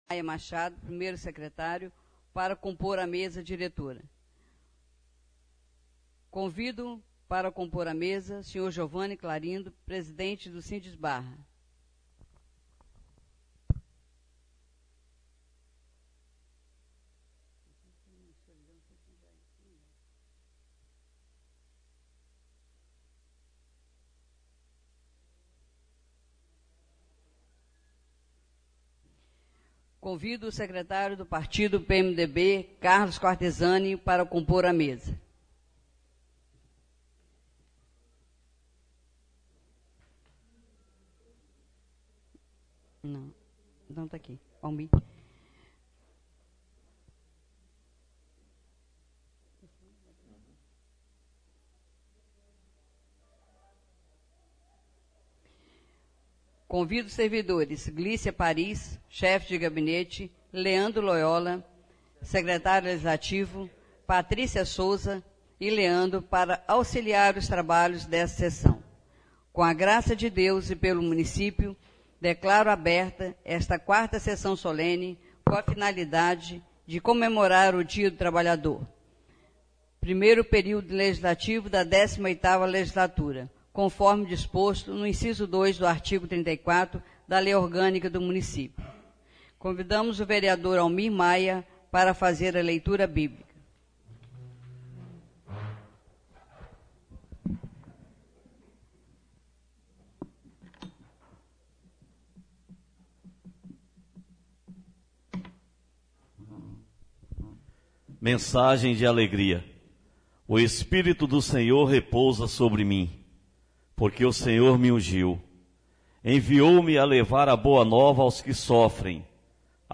SESSÃO SOLENE DIA DO TRABALHO 1 DE MAIO DE 2017